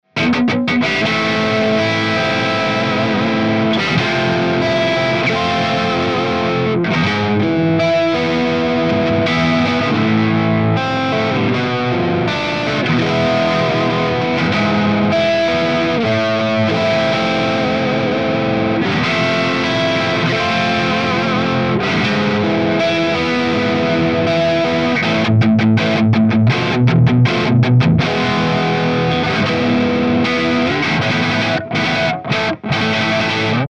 Very simple to get great tones, no switching or options just raw jaw dropping distortion tone.
With some increadable lead tones and dark heavy rhythm tones.
Chords
RAW AUDIO CLIPS ONLY, NO POST-PROCESSING EFFECTS
Hi-Gain